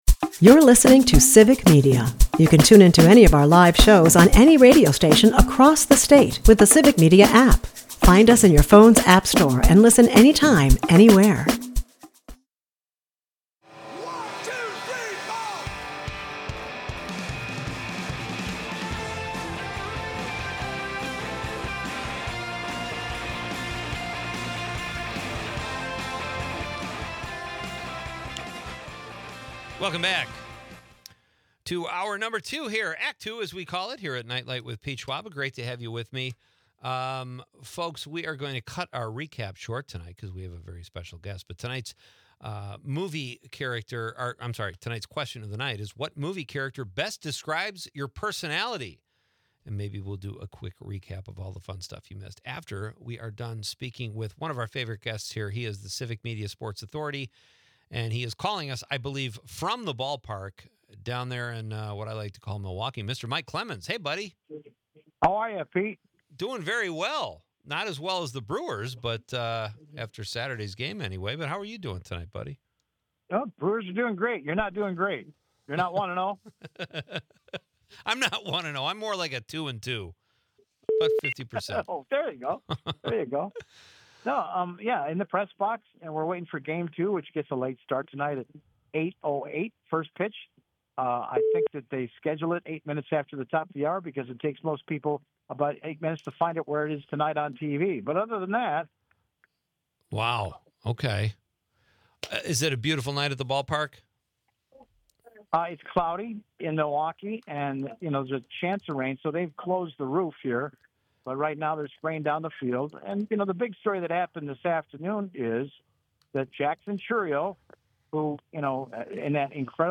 Plus, listener interactions and quirky movie character comparisons spice up the night.